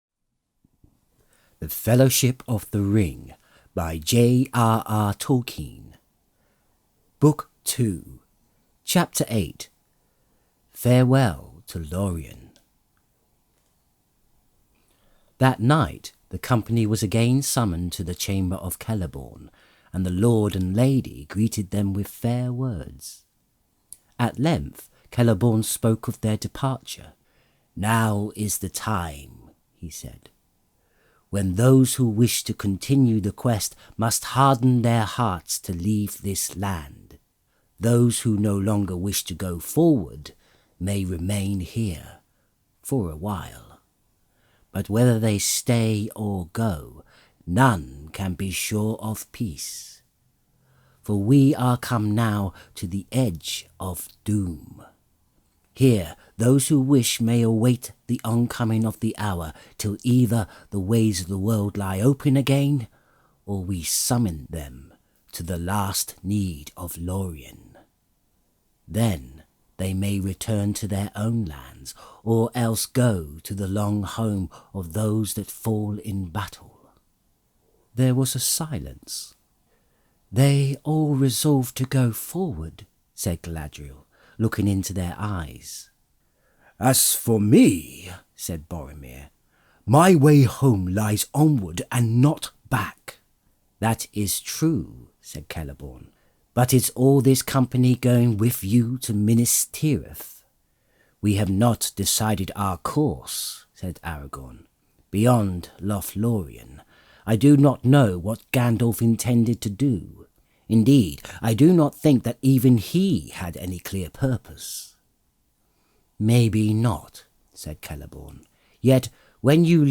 Lord Of The Rings And Hobbit Audiobooks (J.R.R. Tolkien)!